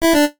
jingles-retro_01.ogg